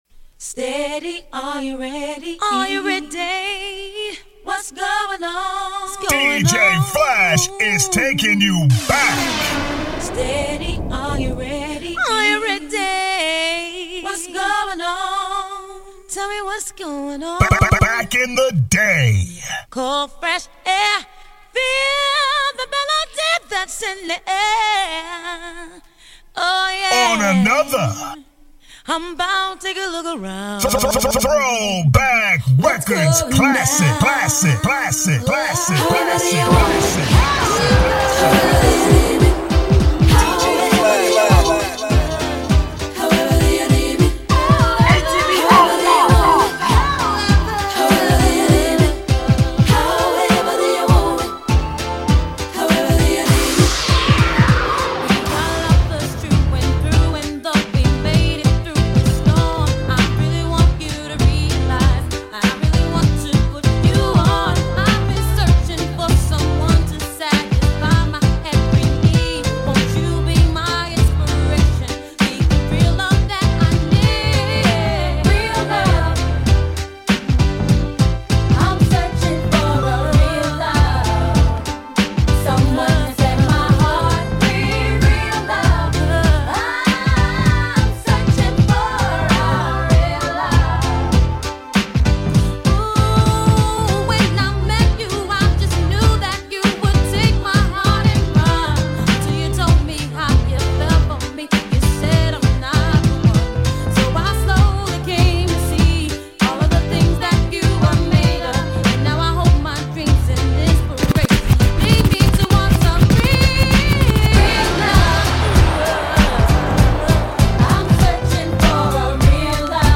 Hip Hop Soul